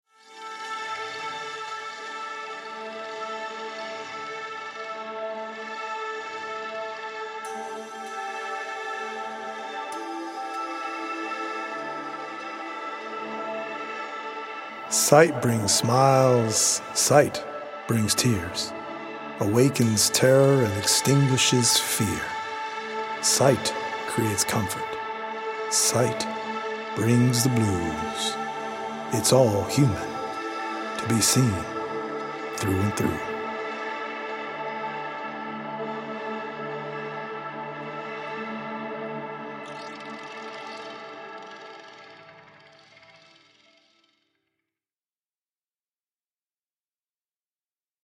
Senses is a healing audio-visual poetic journey through the mind-body and spirit that is based on 100 original poems written/performed by
healing Solfeggio frequency music